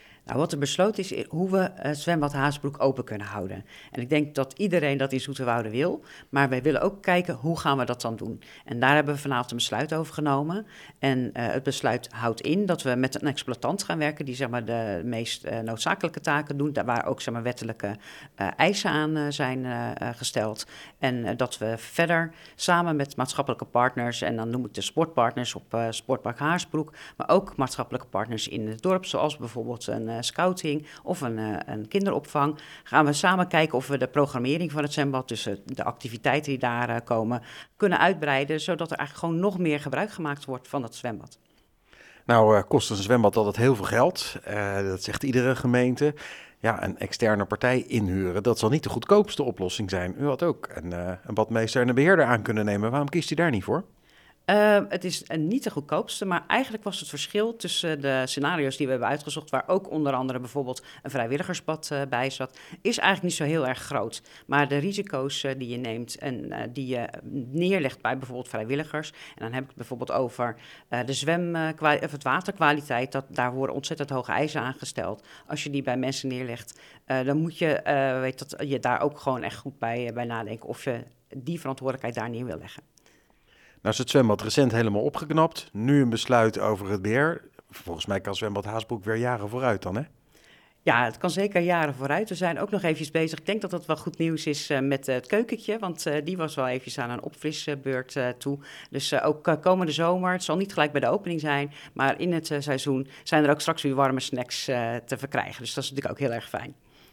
Wethouder Angelique Beekhuizen over de scenario’s voor Zwembad Haasbroek: